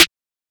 twatsnare2.wav